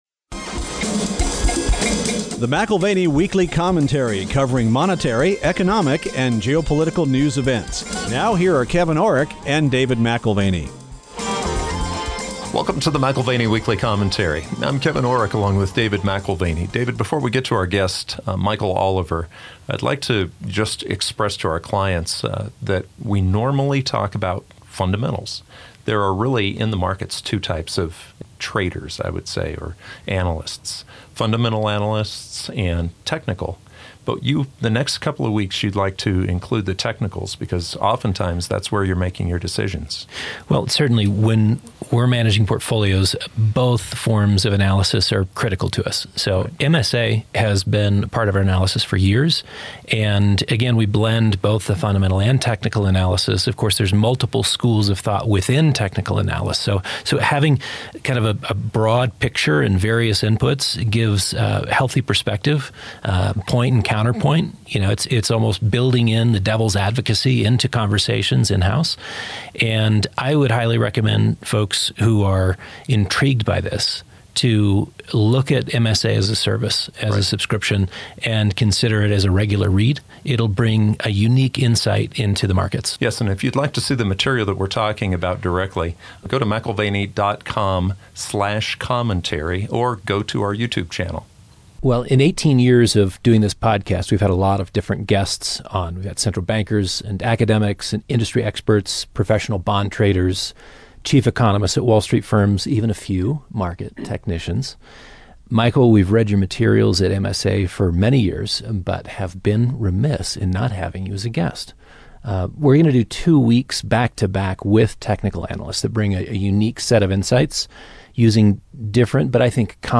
In this conversation, he explains why the gold/S&P 500 breakout matters, why silver may be preparing for a much larger move, and what his momentum models are signaling across multiple asset classes.